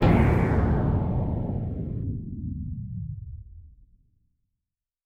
pgs/Assets/Audio/Sci-Fi Sounds/Weapons/Sci Fi Explosion 03.wav at 7452e70b8c5ad2f7daae623e1a952eb18c9caab4
Sci Fi Explosion 03.wav